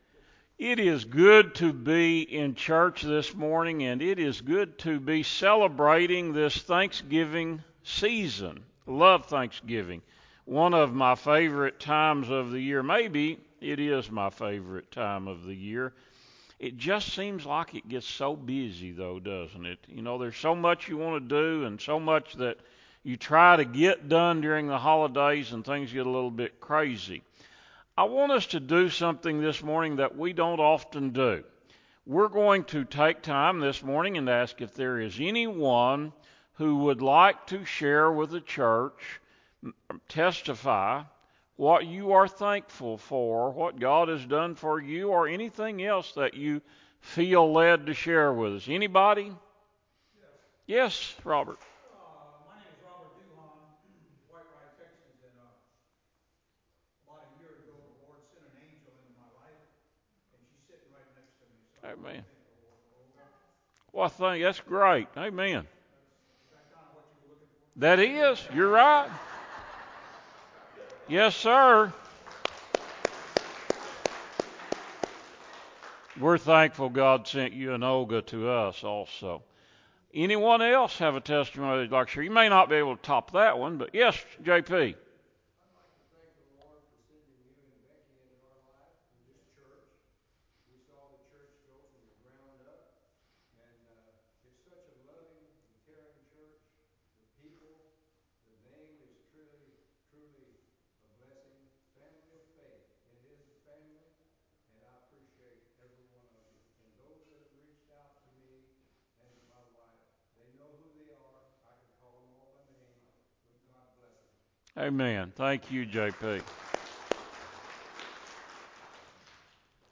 Sermon-CD.mp3